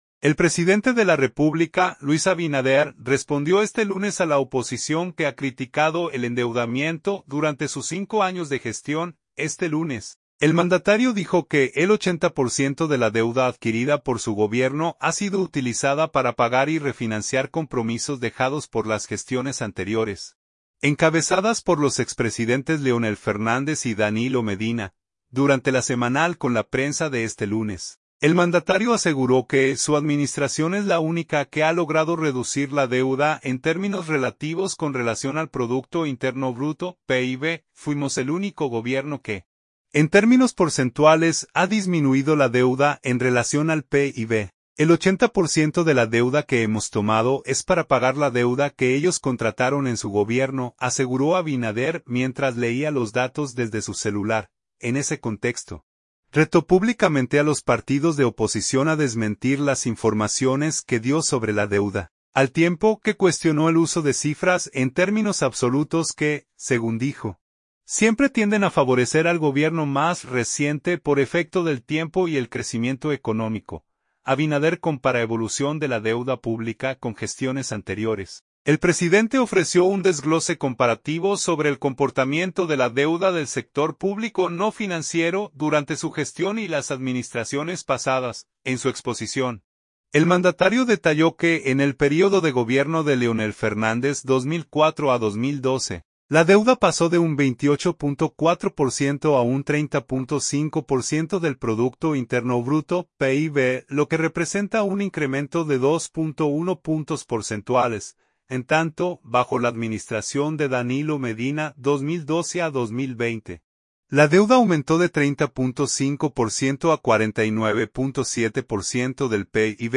Durante LA Semanal con la Prensa de este lunes, el mandatario aseguró que su administración es la única que ha logrado reducir la deuda en términos relativos con relación al Producto Interno Bruto (PIB).
"Fuimos el único gobierno que, en términos porcentuales, ha disminuido la deuda en relación al PIB. El 80 % de la deuda que hemos tomado es para pagar la deuda que ellos contrataron en su gobierno", aseguró Abinader mientras leía los datos desde su celular.